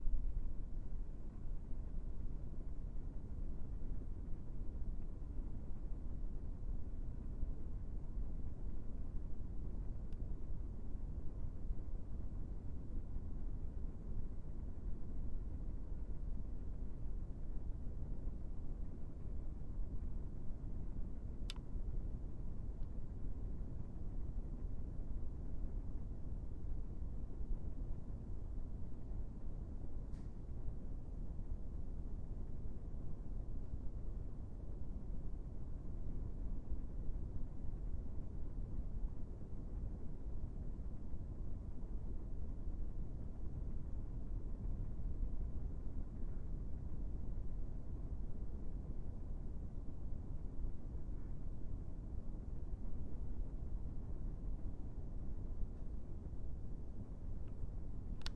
环境低噪音
描述：环境柔软的空调嗡嗡声 使用tascam DR05 24位96000 Hz记录。
Tag: 环境 雄蜂 嗡嗡声 空气调节器